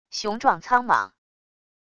雄壮苍莽wav音频